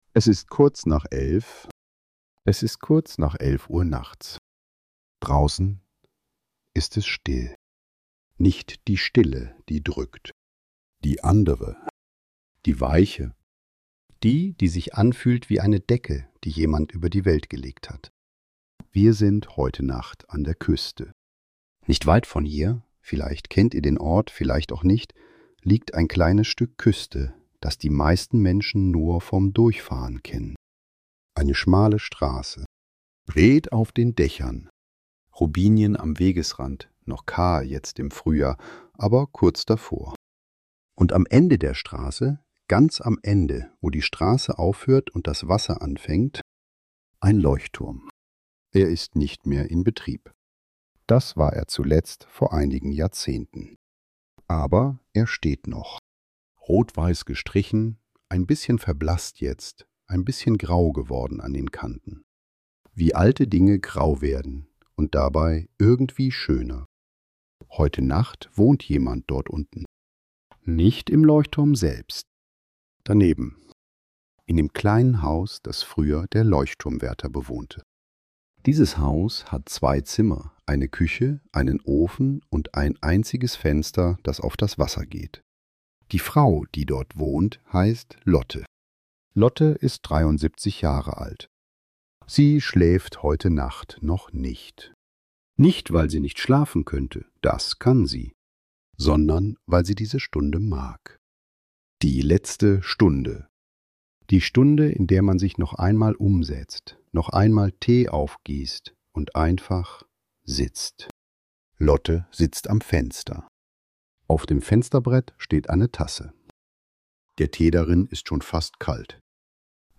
Eine ruhige Nacht an der Küste: Menschen, die zur Ruhe kommen, Gedanken, die leiser werden, und ein Ort, der einfach da ist. Eine sanfte Erzählung über Stille, Alltag und das Loslassen am Ende des Tages.